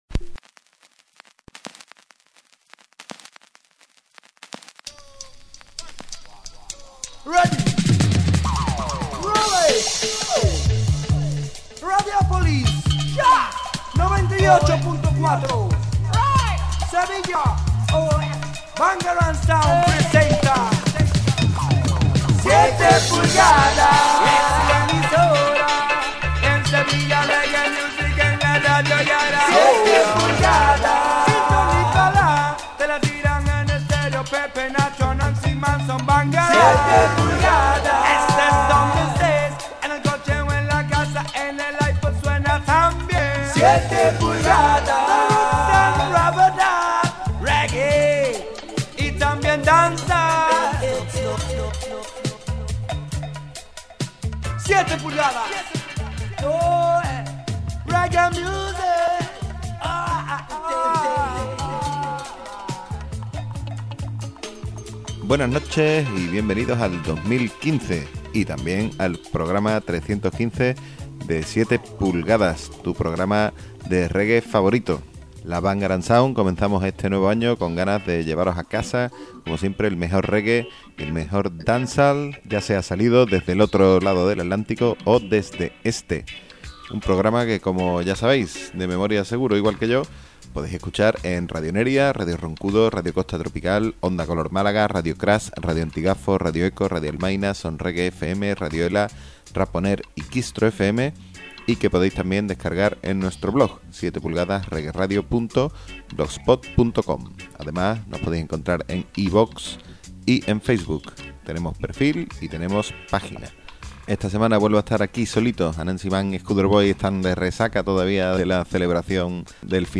serious selection strictly 45s del más puro estilo rockers